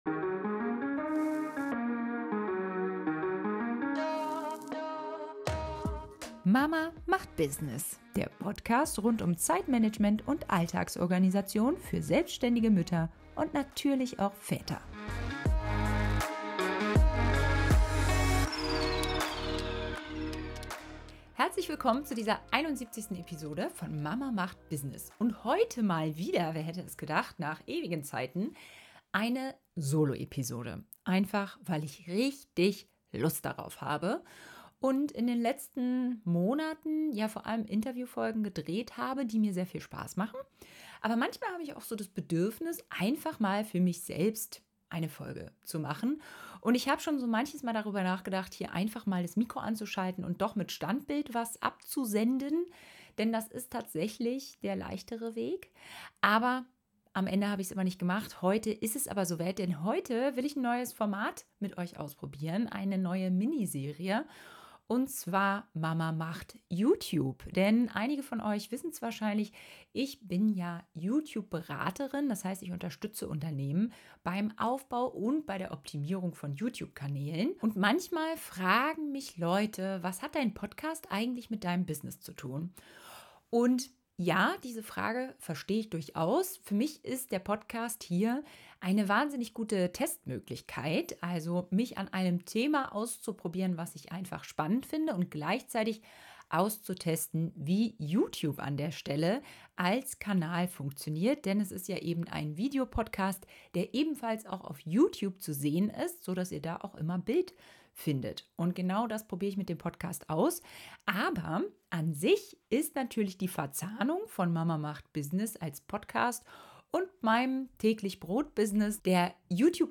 In dieser Solofolge meiner neuen Reihe „Mama Macht YouTube“ zeige ich dir die 3 größten Zeitfresser, die ich bei selbstständigen Müttern immer wieder sehe und wie du sie vermeidest.